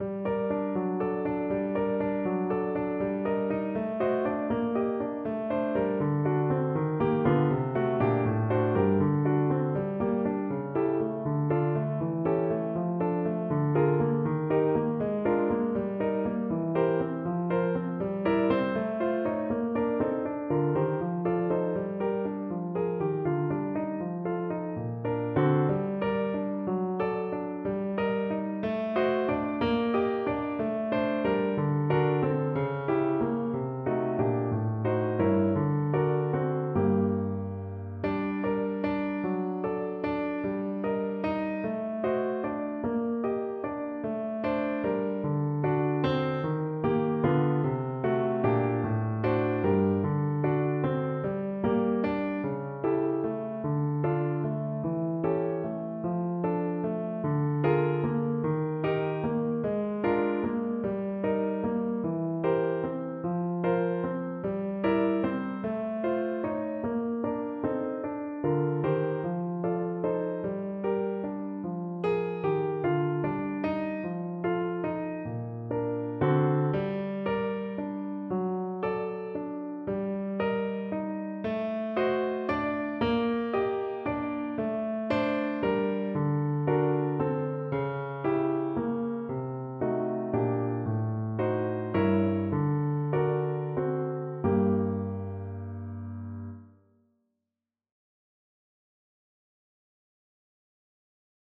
6/8 (View more 6/8 Music)
Classical (View more Classical Soprano Voice Music)